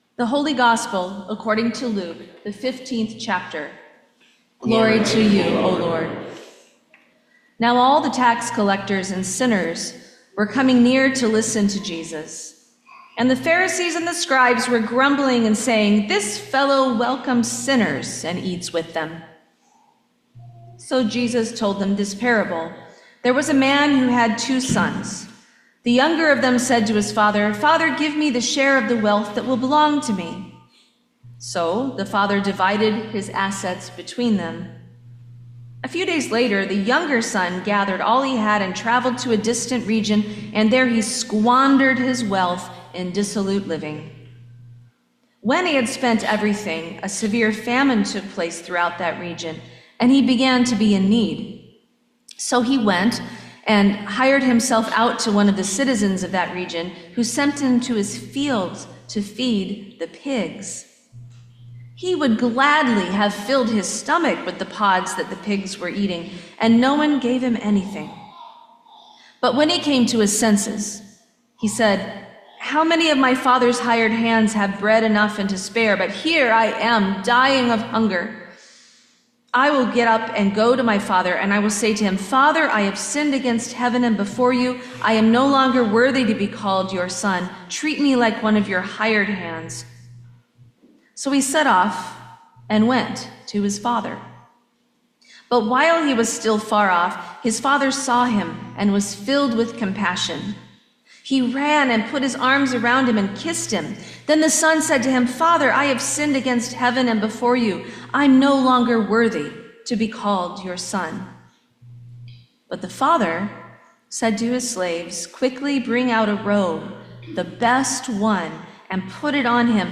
Sermon for the Fourth Sunday in Lent 2025